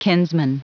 Prononciation du mot kinsman en anglais (fichier audio)
Prononciation du mot : kinsman